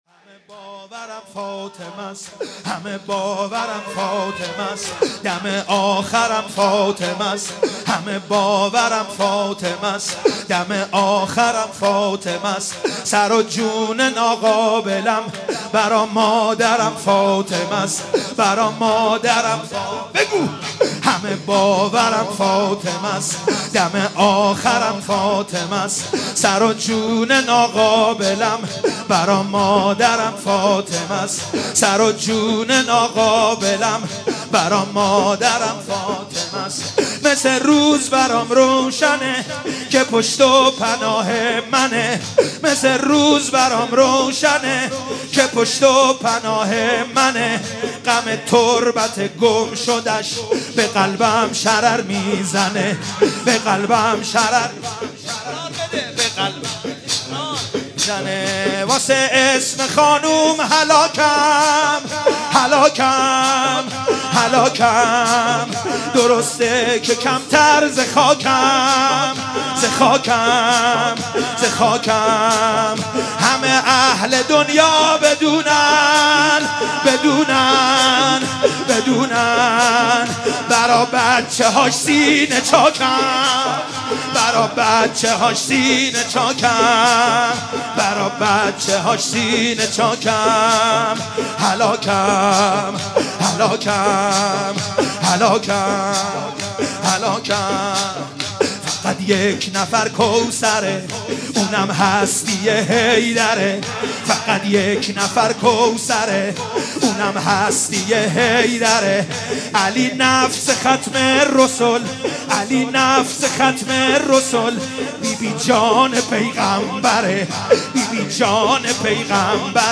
شب 1 فاطمیه 95 - هیئت یازهرا سلام الله علیها قم - شور - همه باورم فاطمس